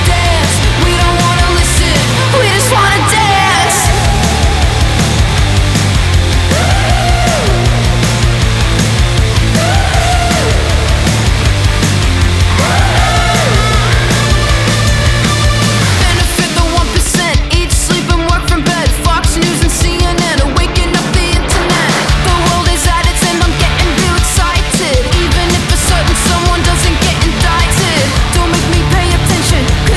Pop Rock Pop Alternative
Жанр: Поп музыка / Рок / Альтернатива